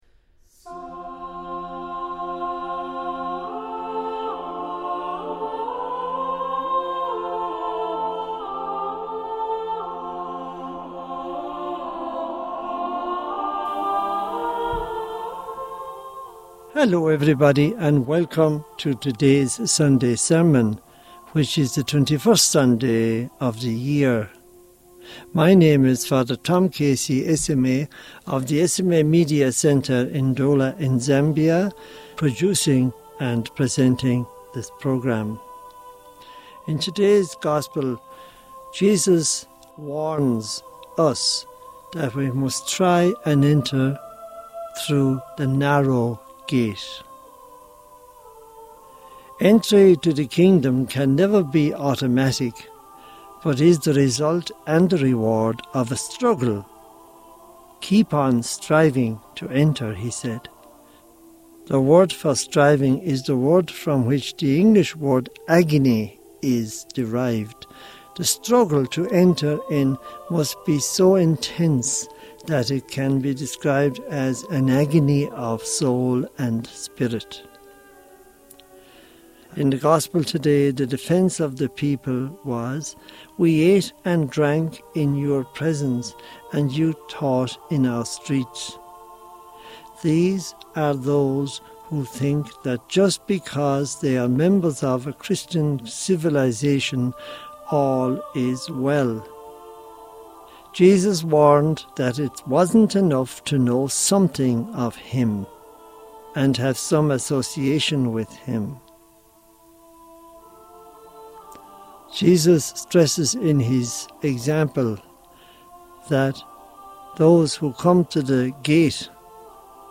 Homily for the 21st Sunday of Year 2025